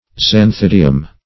Search Result for " xanthidium" : The Collaborative International Dictionary of English v.0.48: Xanthidium \Xan*thid"i*um\, n.; pl.